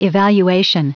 Prononciation du mot evaluation en anglais (fichier audio)
Prononciation du mot : evaluation